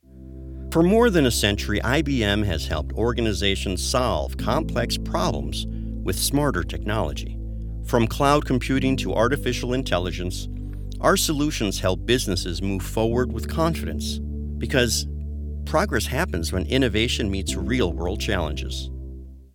Explainer & Web Narration Demos
• A conversational, human delivery
• Broadcast-quality audio for animation or live-action
• A steady, confident tone for demos and how-tos